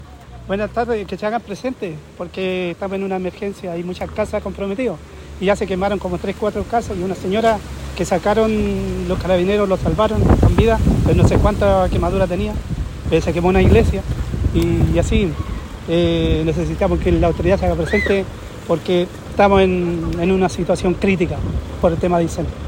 En medio de esta faena, La Radio conversó con uno de los lugareños.
cu-lugareno-incendio.mp3